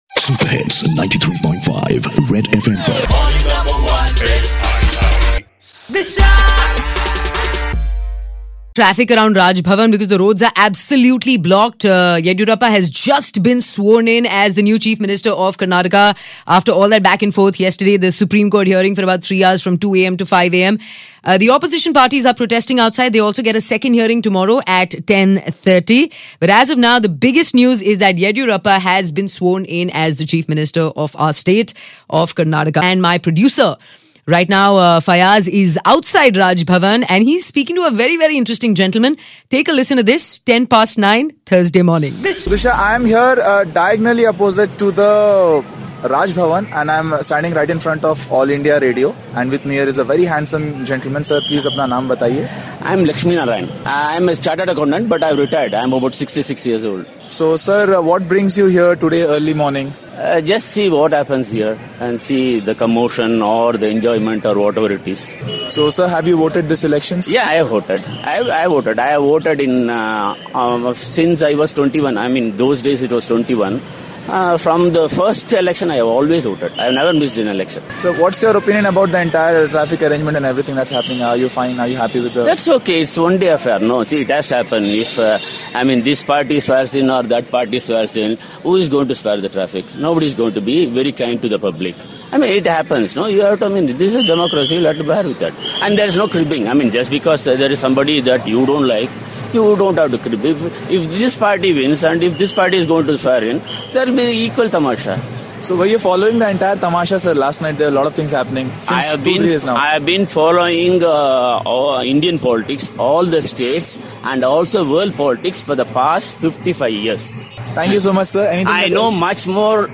Live Ob from Raj Bhavan Road